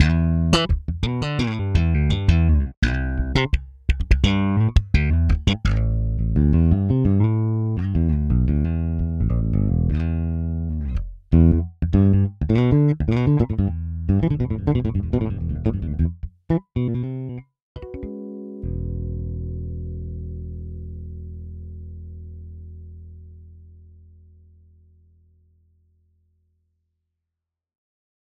Articulation Medley – Modern – Finger (DI)
Articulation-Medley-Modern-Finger-DI.mp3